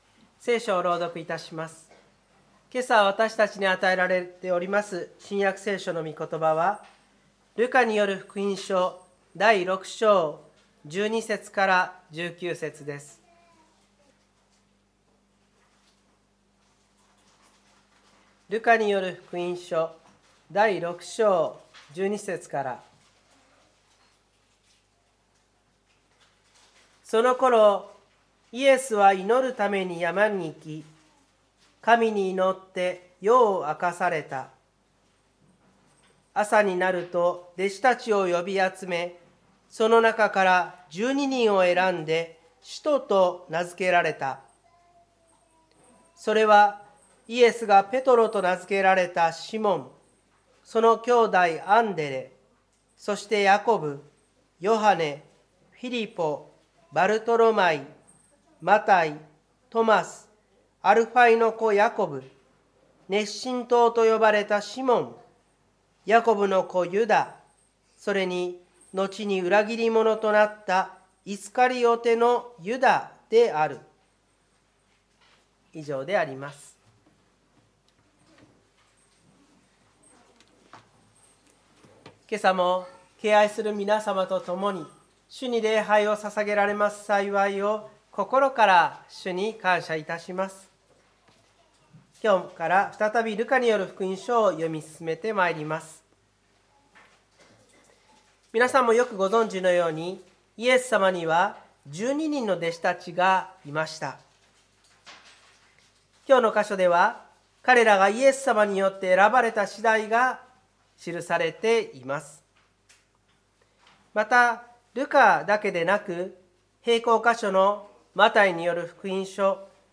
湖北台教会の礼拝説教アーカイブ。
日曜朝の礼拝